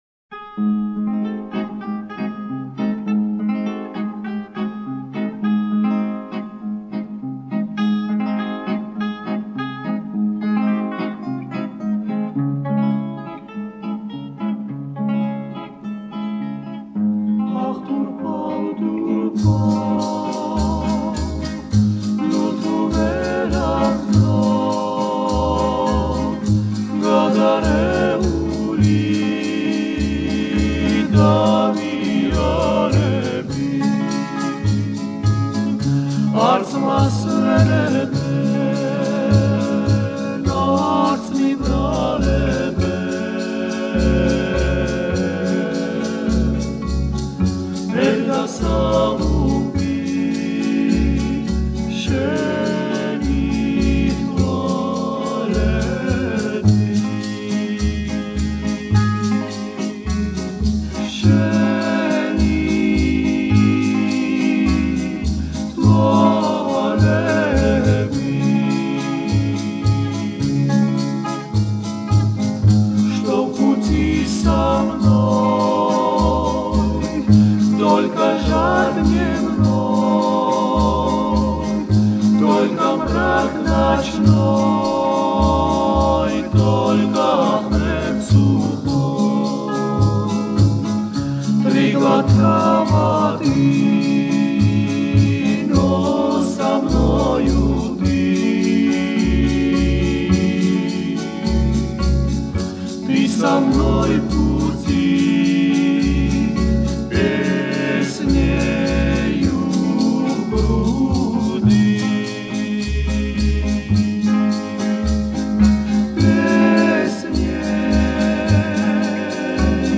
А это народная грузинская, с гранда 1967, русский текст Б. Брянского